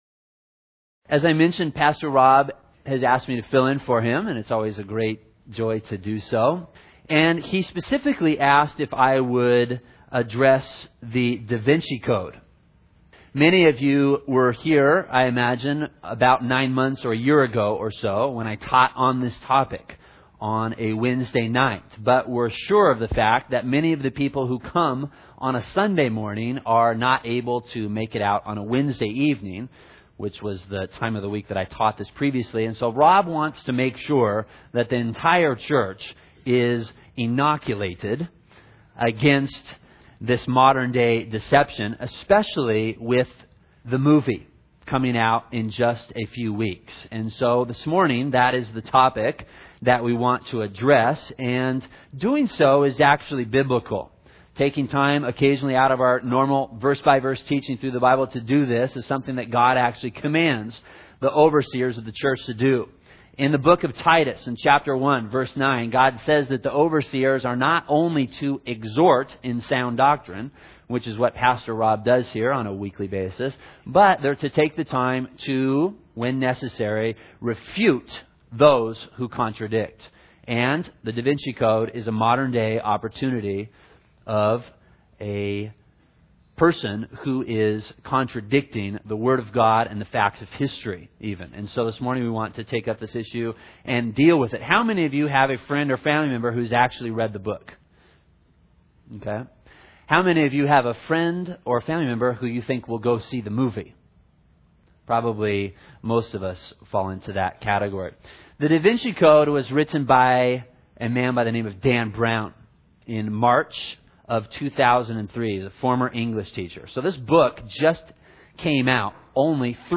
In this sermon, the speaker addresses the issue of the book and movie 'The Da Vinci Code' and how it contradicts the word of God and historical facts.